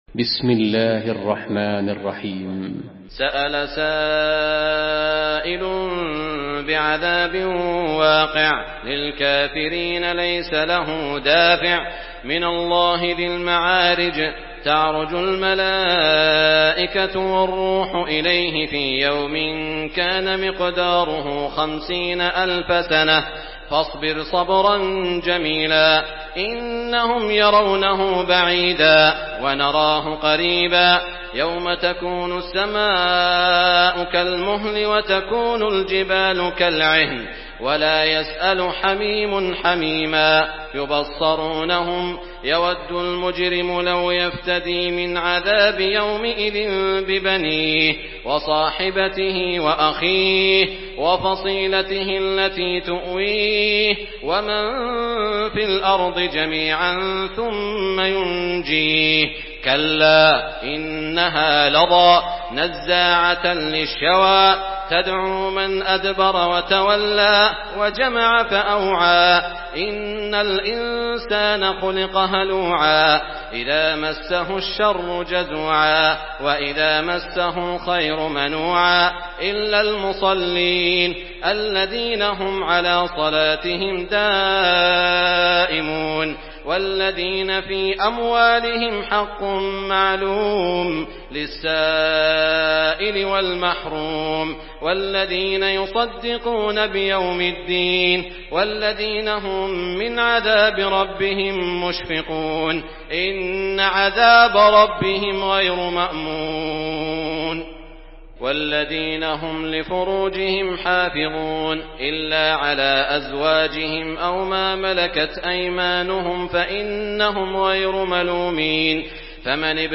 Surah المعارج MP3 by سعود الشريم in حفص عن عاصم narration.
مرتل